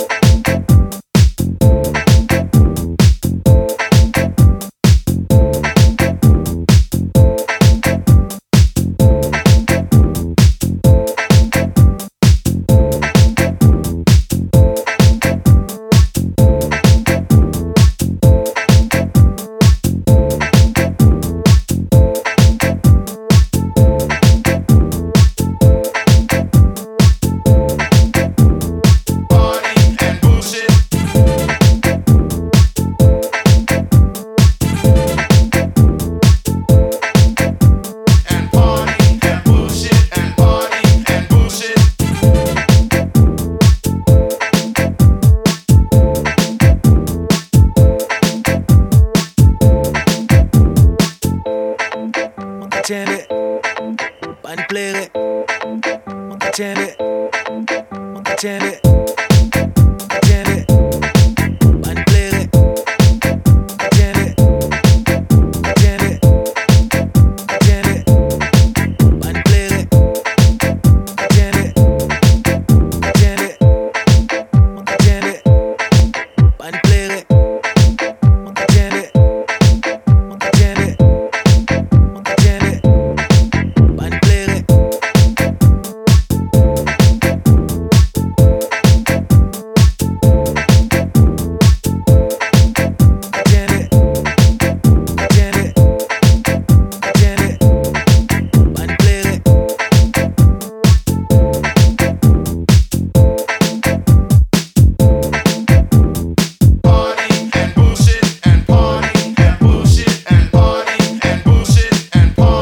mixed with some obscure creole chants